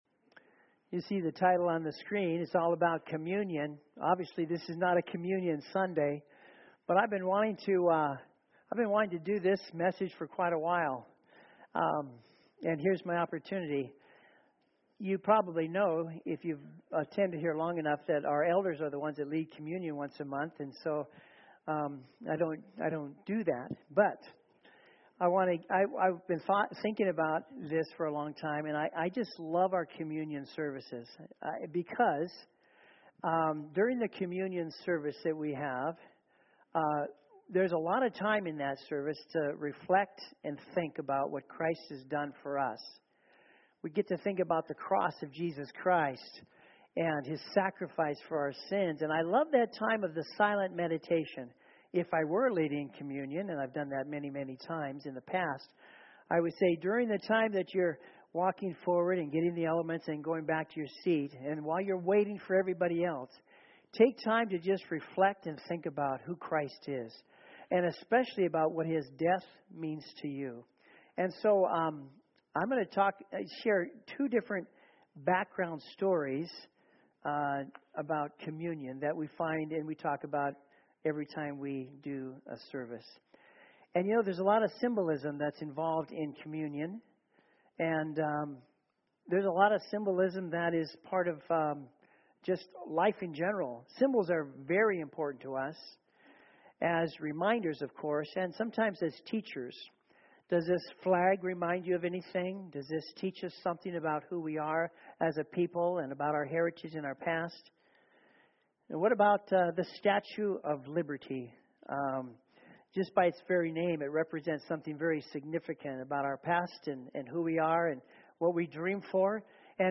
SERMONS Communion Connections